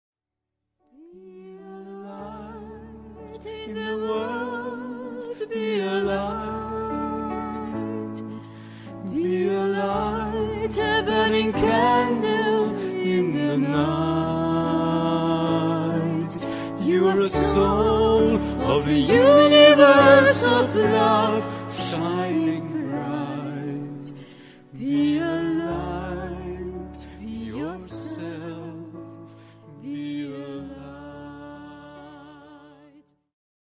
Ihr ausgezeichneter und vielseitiger Pianist